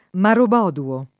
[ marob 0 duo ]